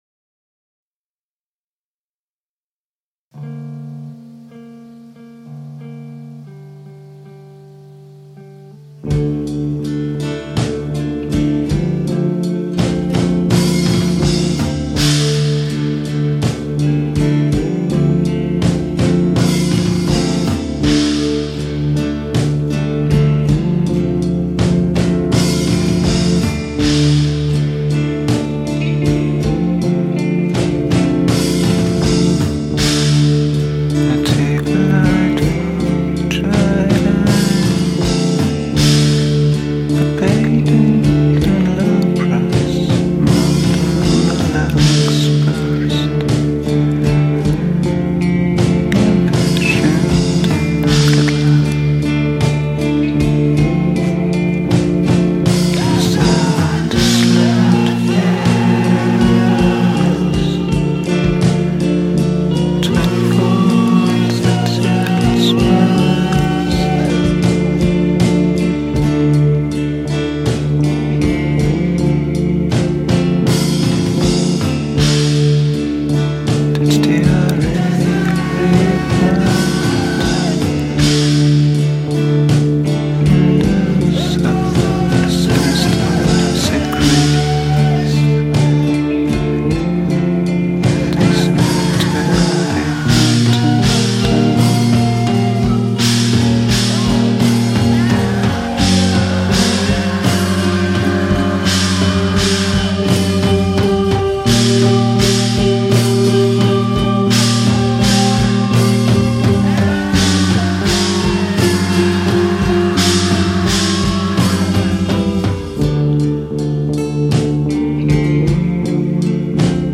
cheap recording
from the rehersal room